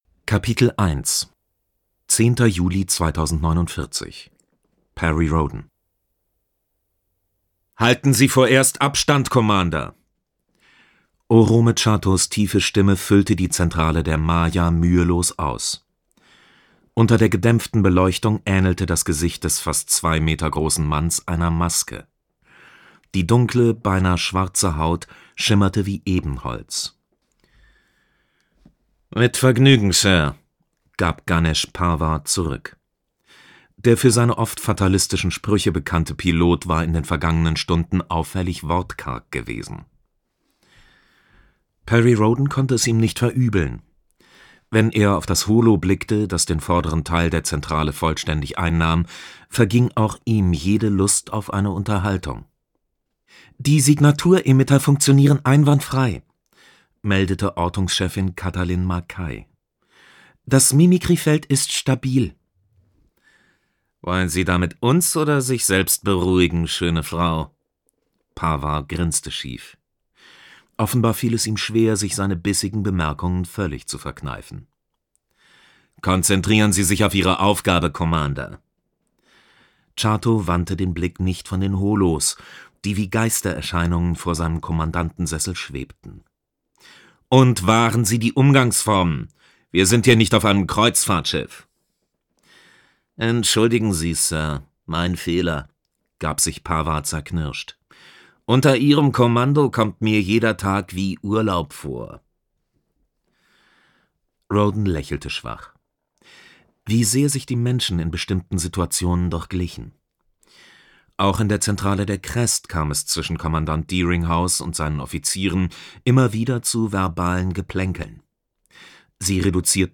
Zusätzliche Formate: E-Book, Hörbuch